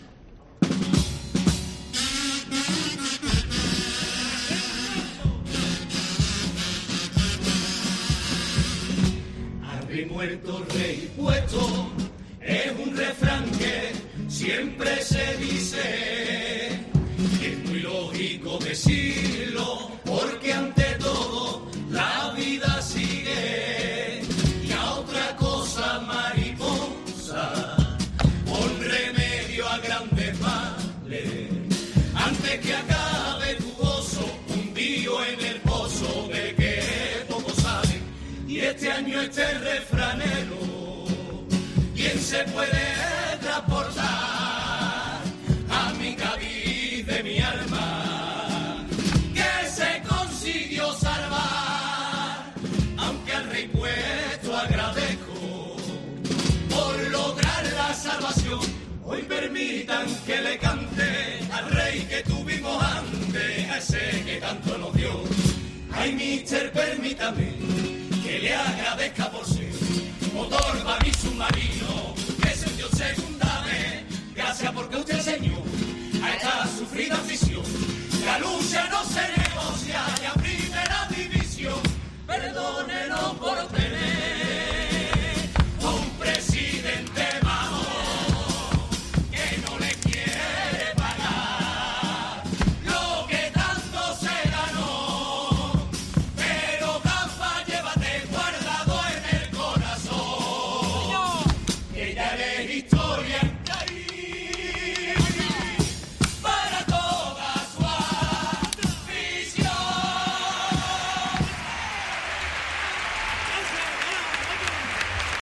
Carnaval